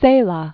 (sālä, thā-), Camilo José 1916-2002.